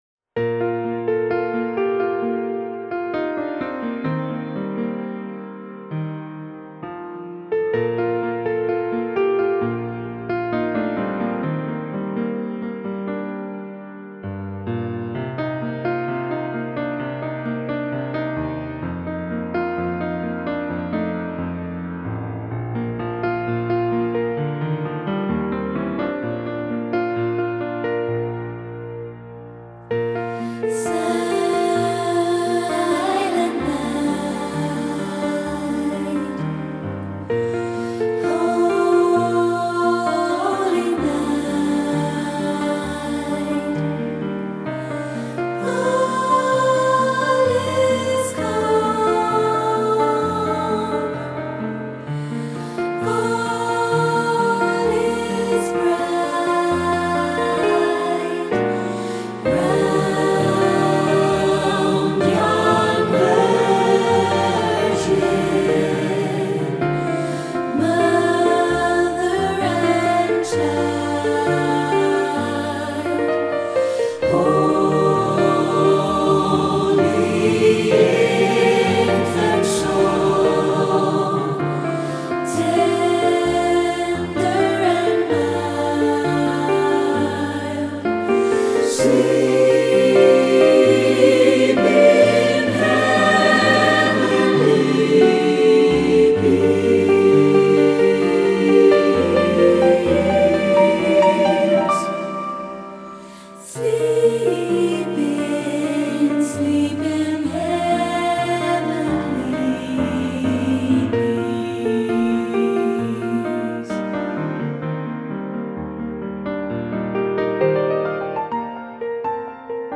Choir and Piano
Voicing: SATB